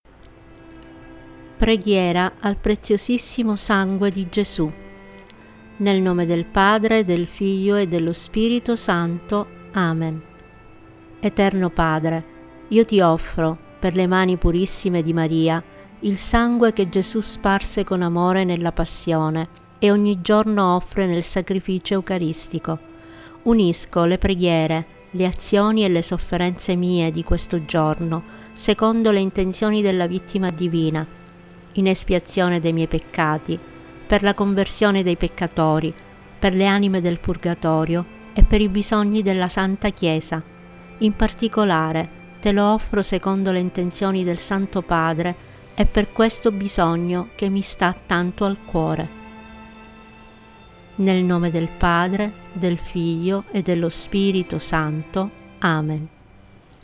Preghiera-al-preziosissimo-Sangue-audio-e-musica-Tempo-di-preghiera.mp3